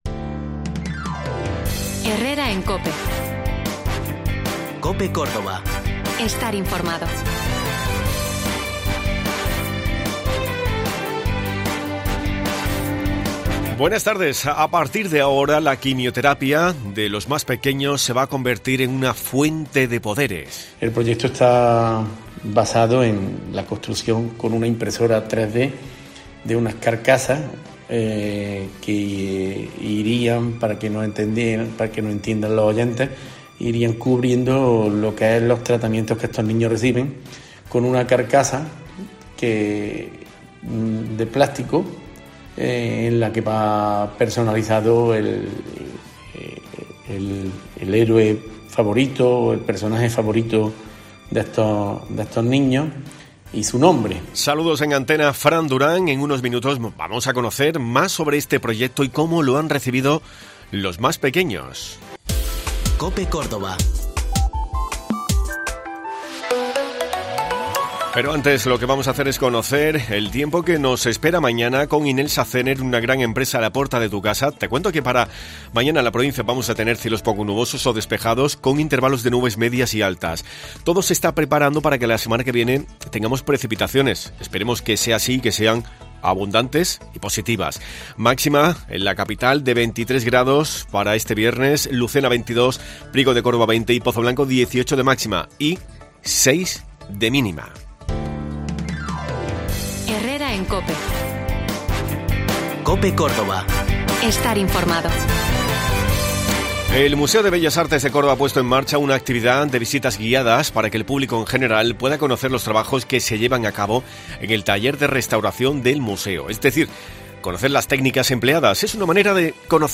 Hoy te hemos contado un proyecto muy bonito, porque los más pequeños que se encuentran en la Unidad de Oncohematología Pediátrica del Hospital Universitario Reina Sofía, ahora puden someterse a sus sesiones con unas cápsulas con poderes. Para conocer más de cerca este proyecto hemos hablado con el Ayuntamiento de Montemayor, porque ha tenido mucho que ver en ello.